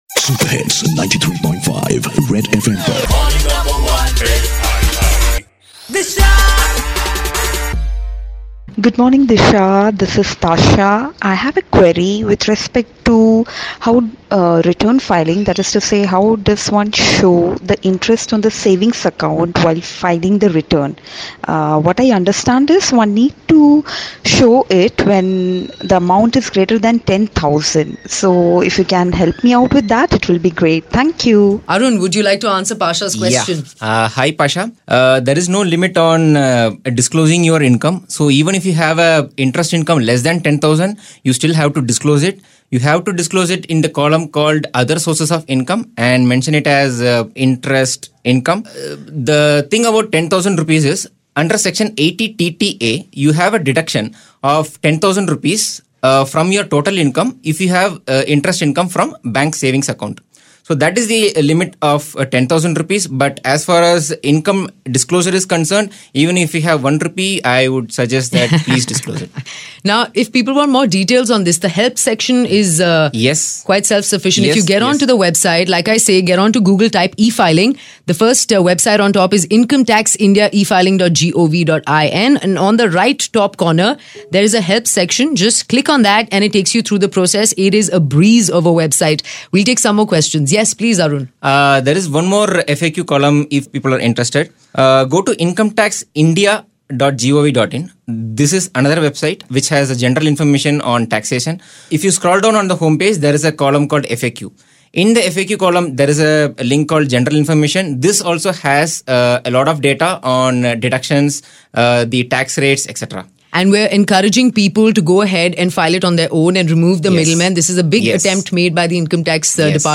Listener queries solved in the studio